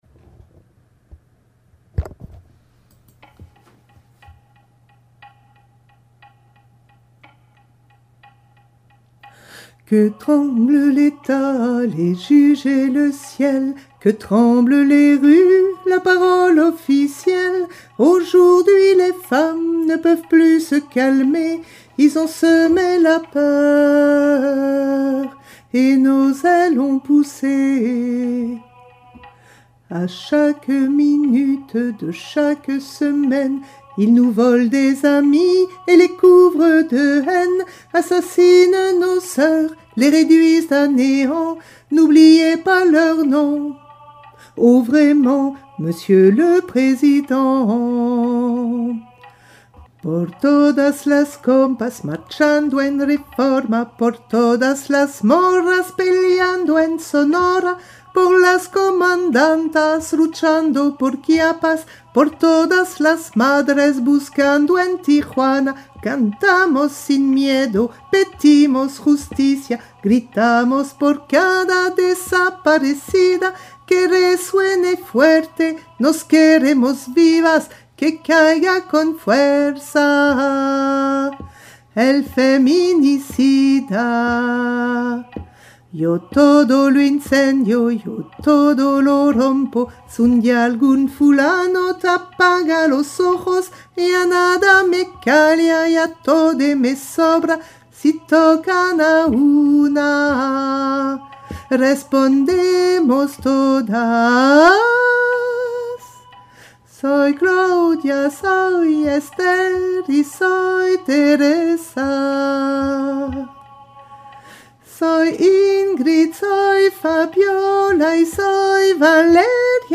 pour une fois, ce sont les voix basses qui ont le lead :
Cancion voix basse
cancion-voix-basse.mp3